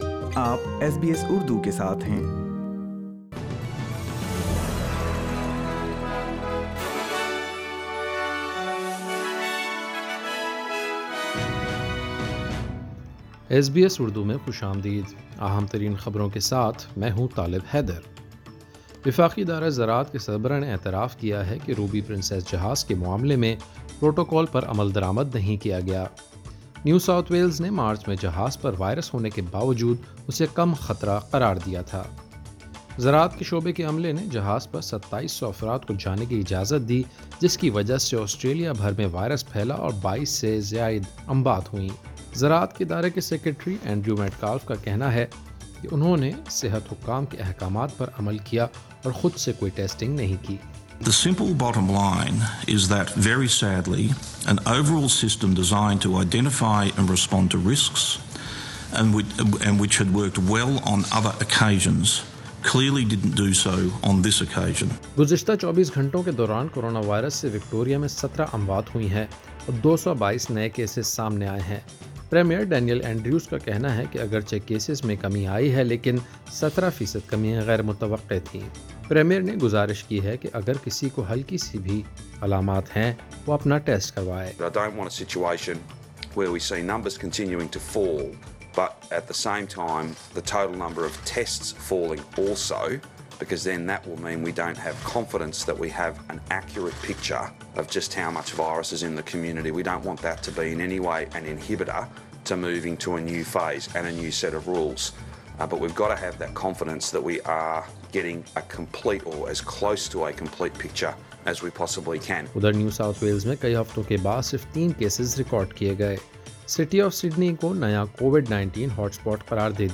daily_news_18.8.20.mp3